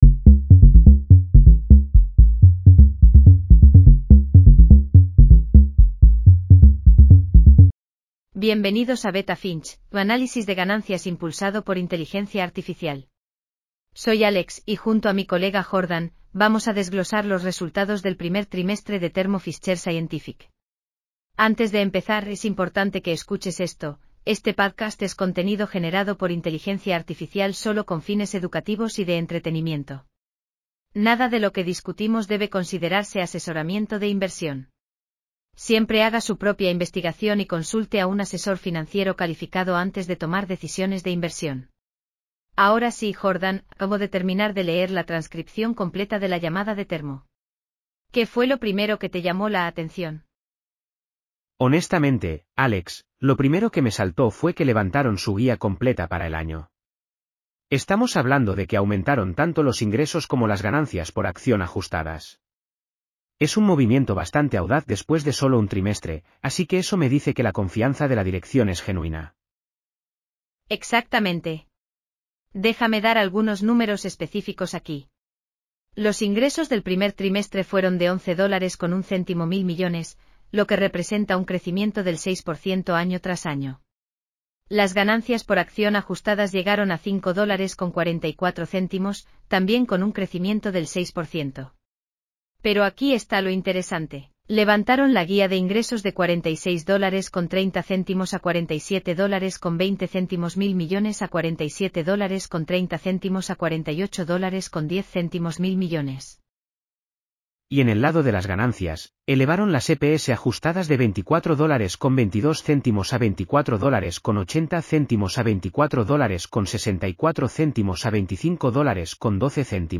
Bienvenidos a Beta Finch, tu análisis de ganancias impulsado por inteligencia artificial.